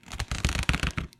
锈蚀的塑料袋
描述：生锈的塑料袋。我打开一个薄的塑料购物袋。您可以听到袋子沙沙声。使用ZOOM H2n进行修复。
标签： 塑料 塑料袋 生锈 放大H2N
声道立体声